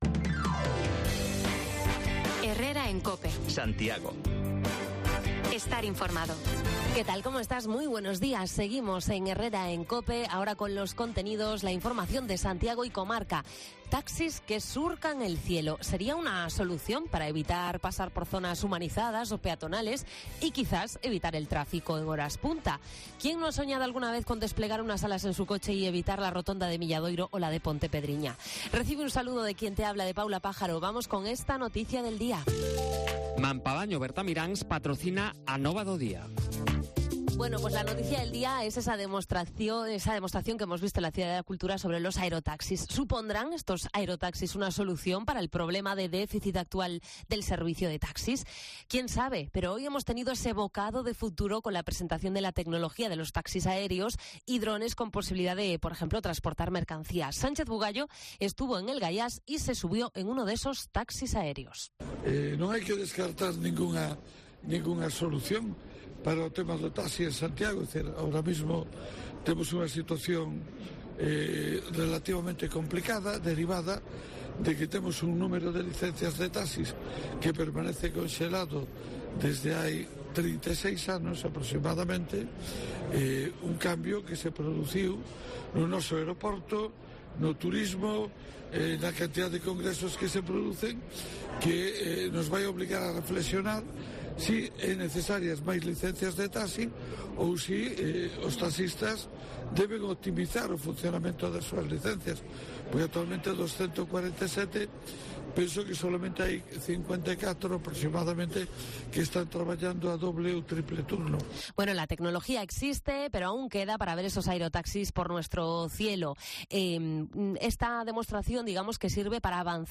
También bajamos los micrófonos a la calle para recoger opiniones sobre el anuncio de bajadas de impuestos